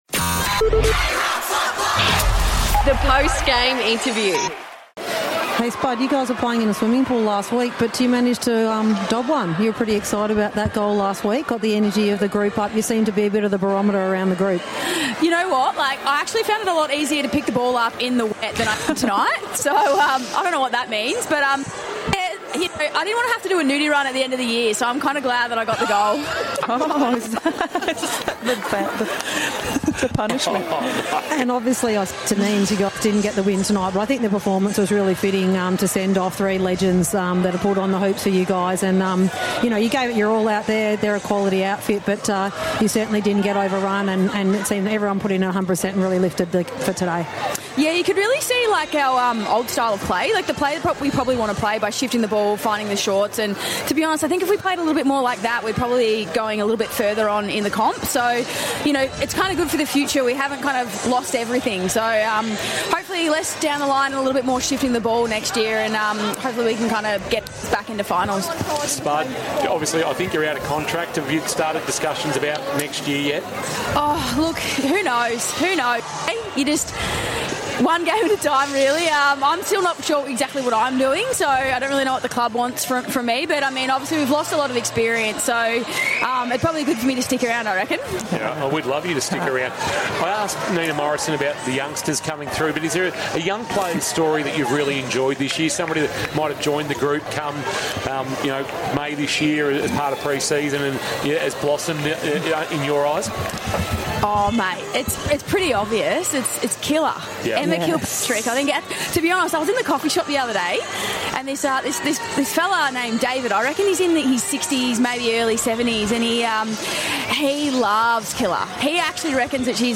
2025 - AFLW - Round 12 - Geelong vs. Melbourne- Post-match interview